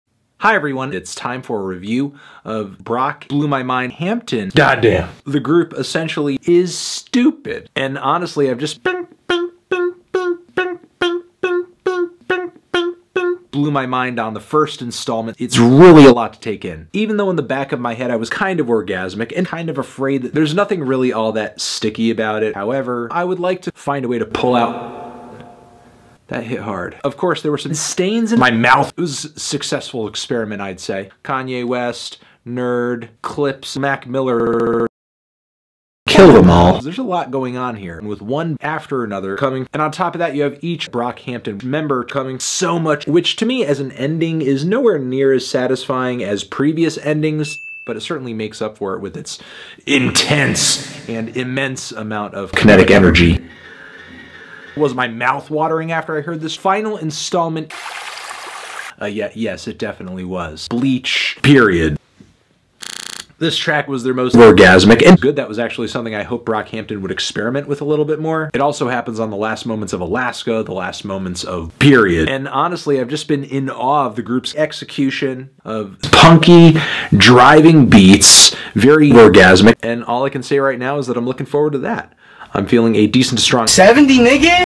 BPM124
Audio QualityCut From Video